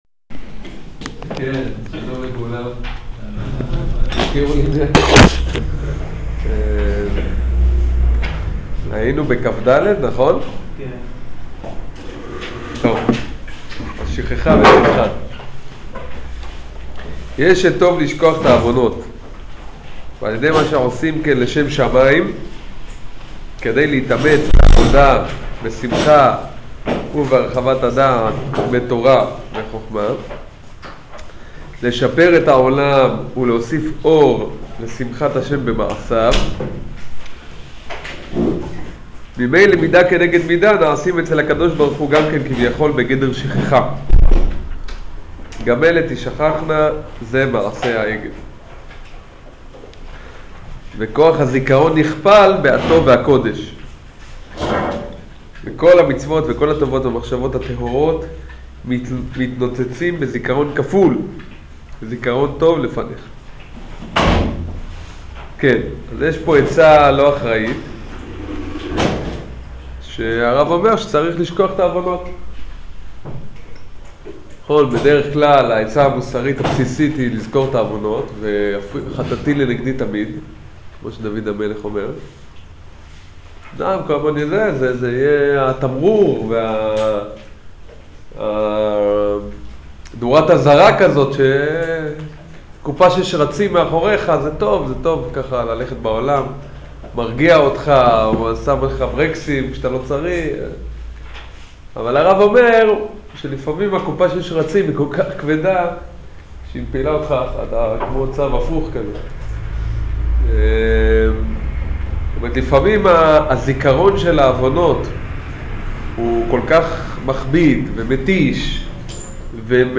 שיעור פסקאות כד